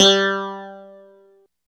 27 CLAV G3-R.wav